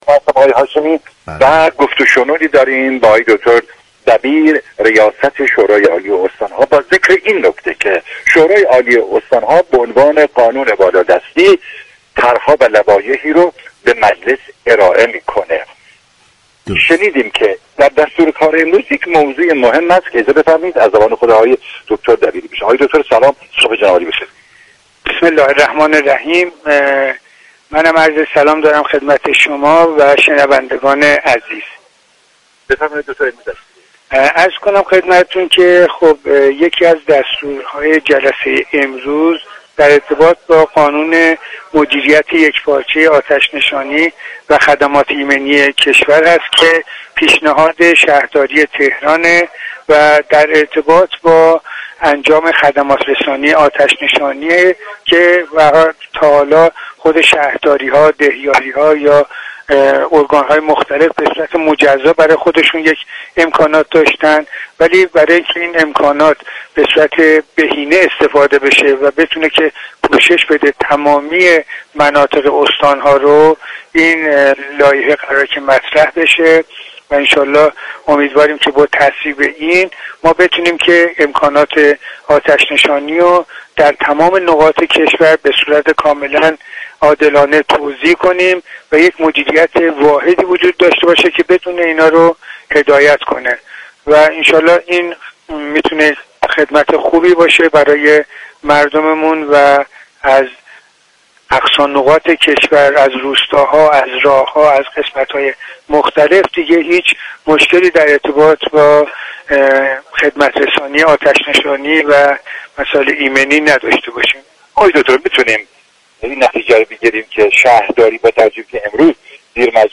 به گزارش پایگاه اطلاع رسانی رادیو تهران، شهرام دبیری رئیس شورای عالی استان‌ها در گفت و گو با «پارك شهر» اظهار داشت: سازمان آتش‌نشانی یك سازمان دولتی است كه در ایران زیر نظر شهرداری هر شهر فعالیت می‌كند.